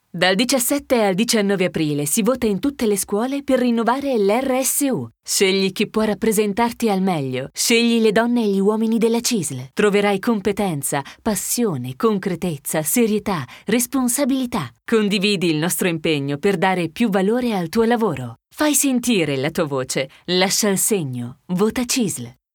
Di seguito gli Spot audio della Cisl Scuola per la campagna elezioni Rsu del 17,18 e 19 aprile 2018.
spot-audio-rsu-cisl-scuola.mp3